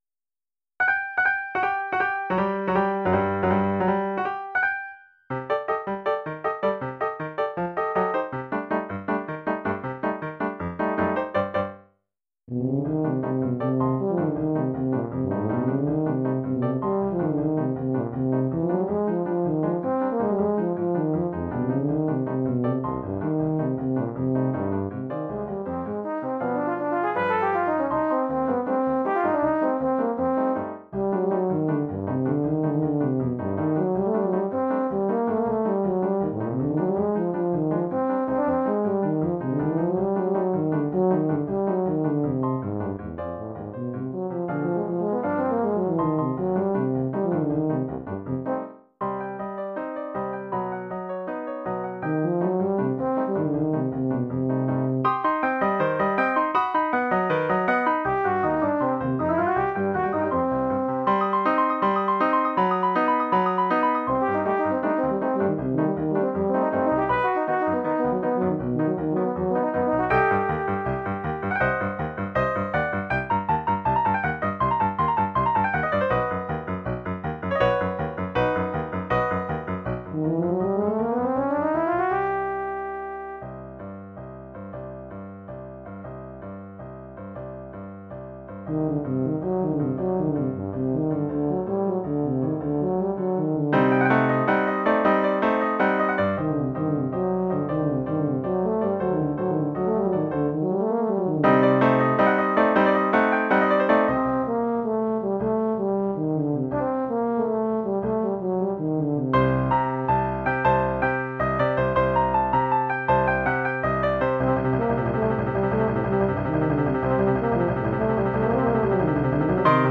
Formule instrumentale : Saxhorn basse/Tuba et piano
Oeuvre pour saxhorn basse / euphonium /
tuba et piano.